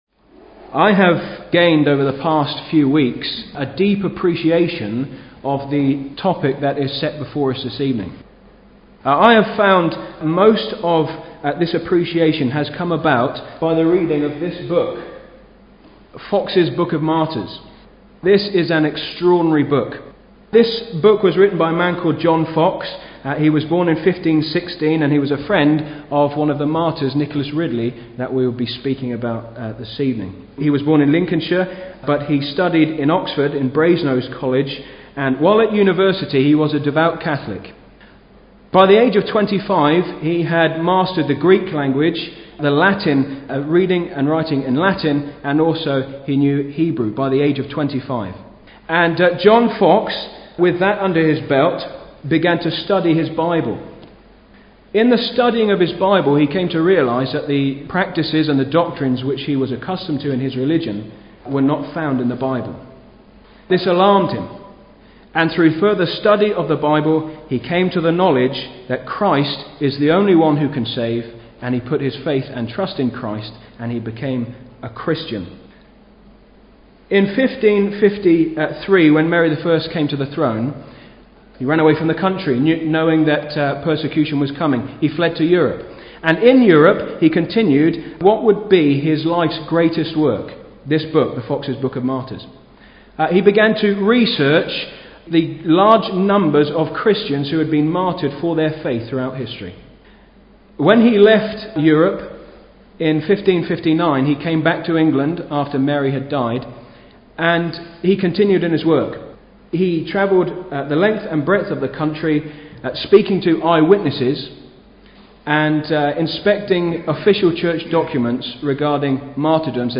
(Message preached 4th Oct 2015)